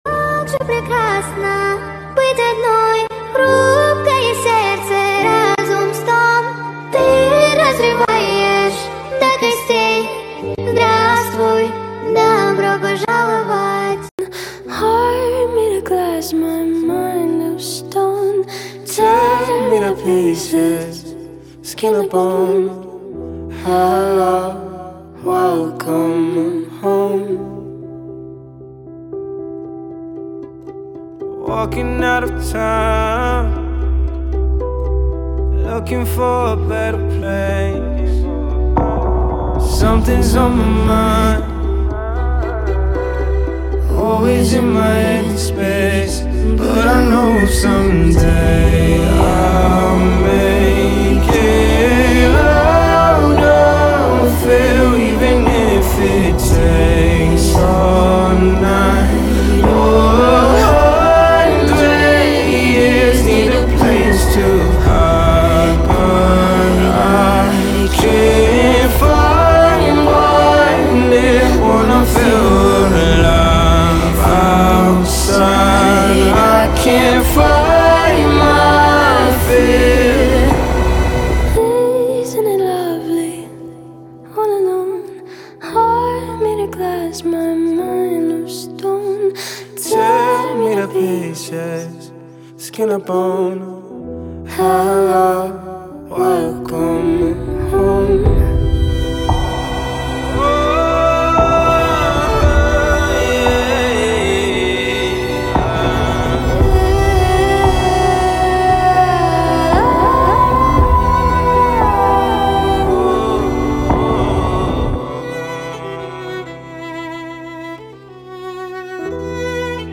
кавер на русском поет девушка